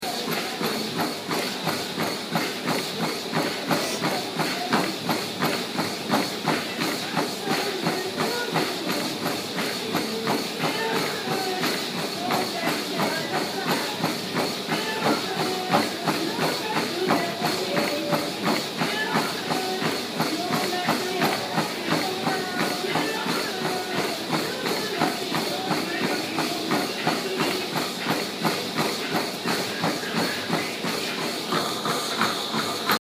Location: On the treadmill at the Hofstra University Fitness center on February 5th, 2016 at approximately 7:00am
Sounds heard: footsteps on treadmill, treadmill moving, unidentifiable popular song in background, sniffling and breathing